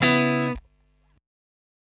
Dbm7_14.wav